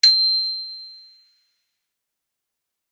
bicycle-bell_04
bell bells bicycle bike bright chime chimes clang sound effect free sound royalty free Memes